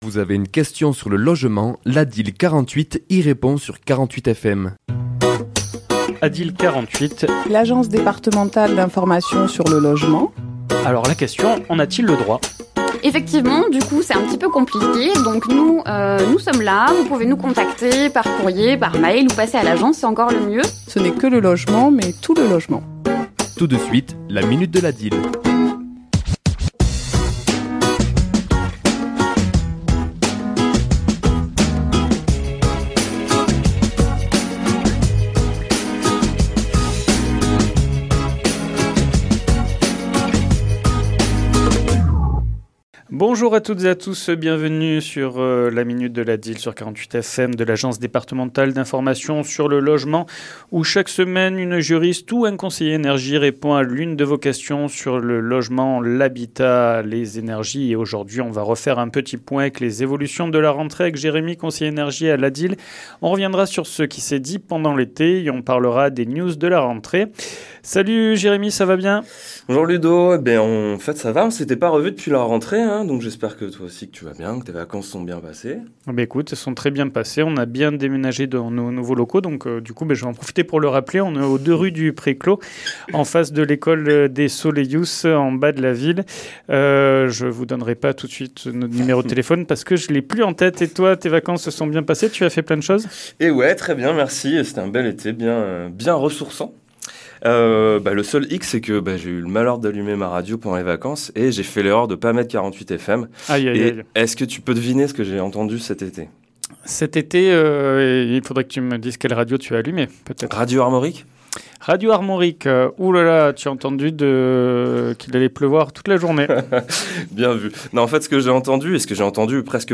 Chronique diffusée le mardi 23 septembre à 11h et 17h10